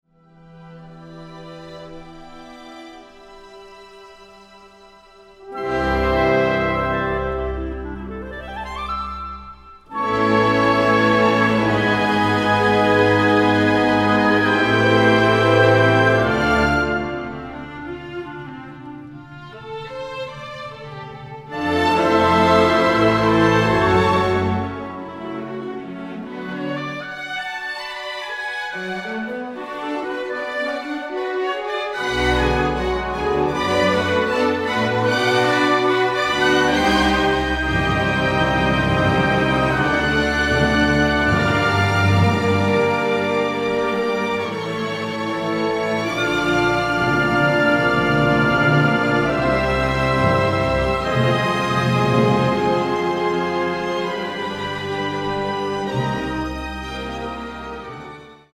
Symphony No. 1, 'Glencree'